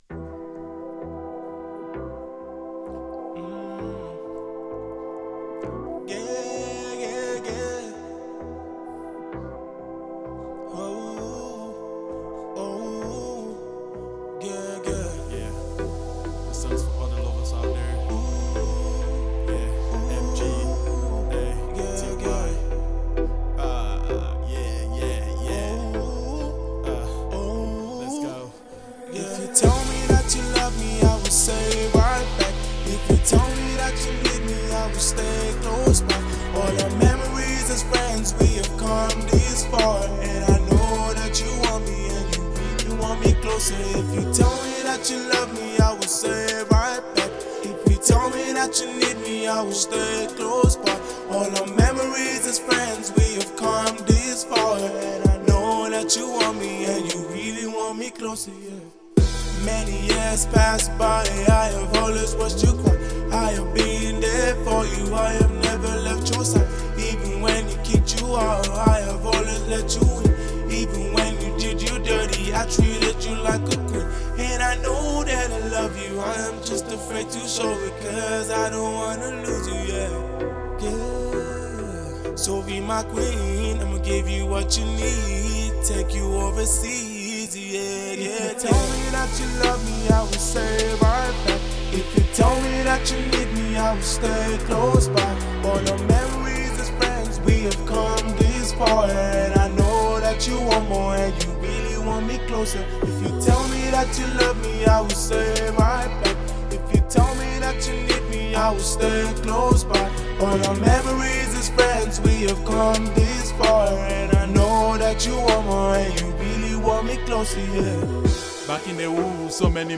Cameroonian singer